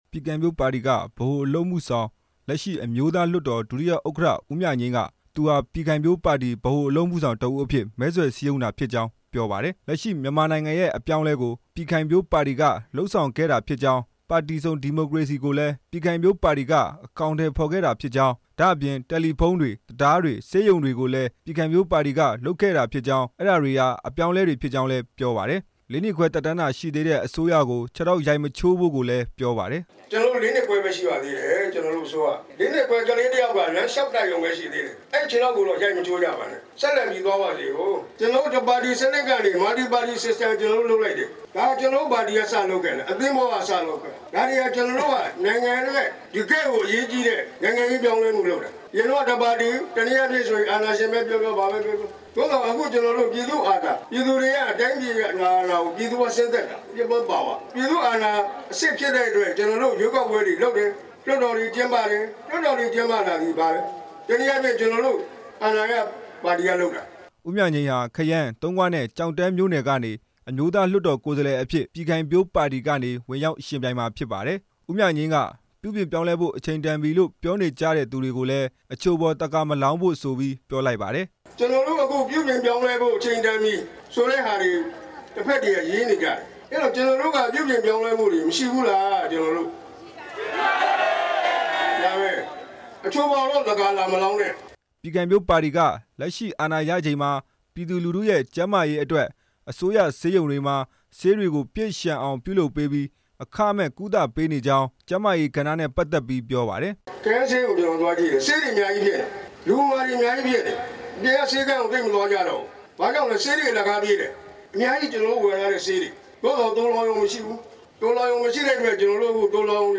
ရန်ကုန်တိုင်းဒေသကြီး သင်္ဃန်းကျွန်းမြို့နယ် သုဝဏ္ဏမြို့ဦးစေတီအနီးက အားကစားကွင်းမှာ ပြည်ခိုင်ဖြိုးပါတီက မဲဆွယ်ပွဲပြုလုပ်ခဲ့ပါတယ်။